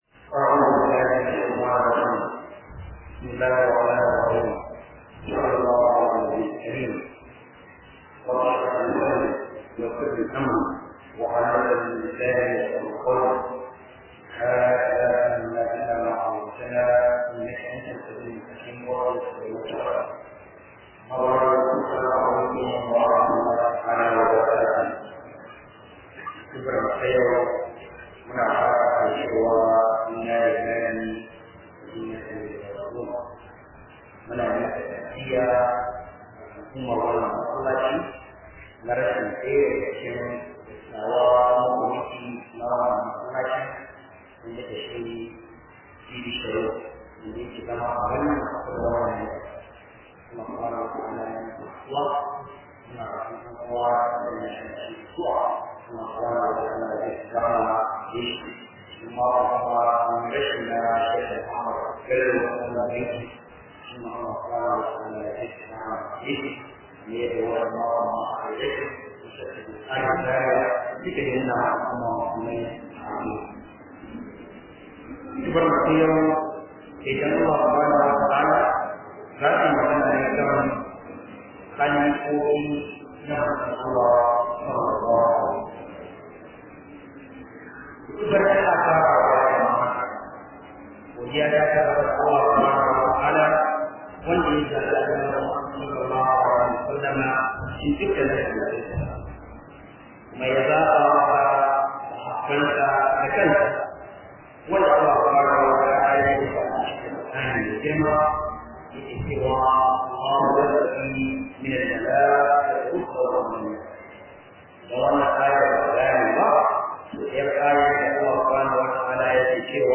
029 Khudubah kan Sahabbai .mp3